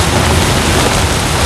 tyres_grass_roll.wav